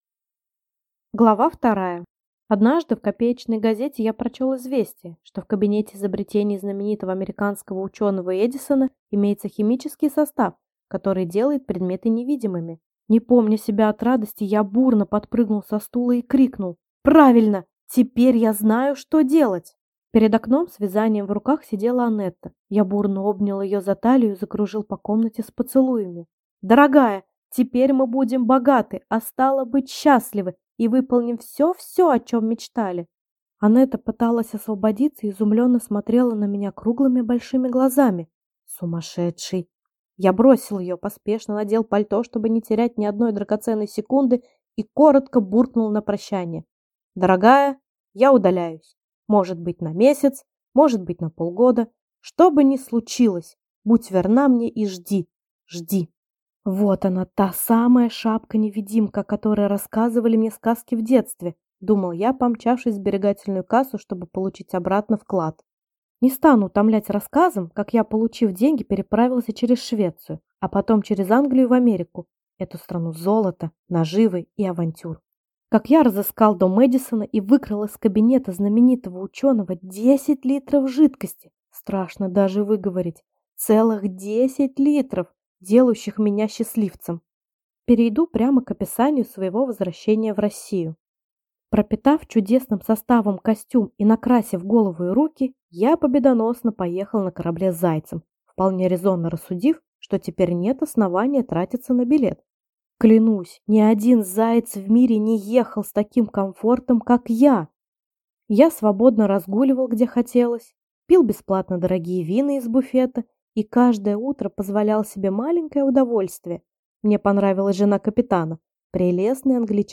Аудиокнига Глупое счастье | Библиотека аудиокниг